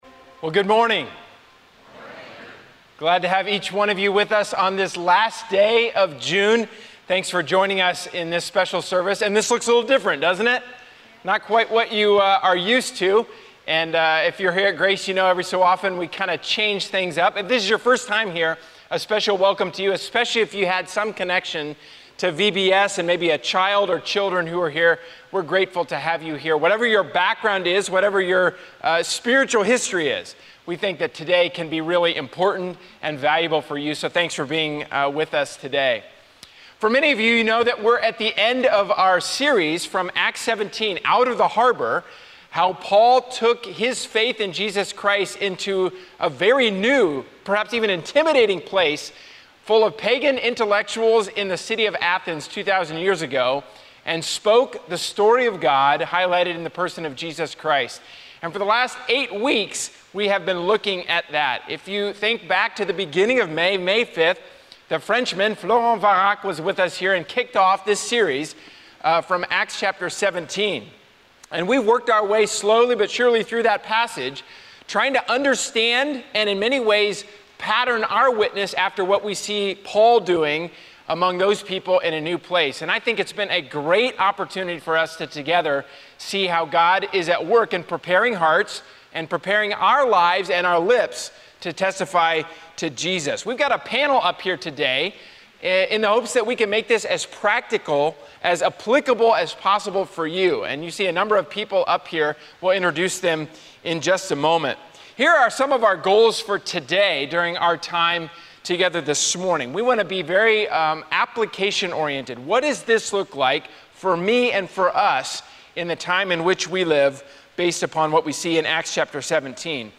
Guest panelists discuss takeaways from our “Out of the Harbor” sermon series in Acts 17.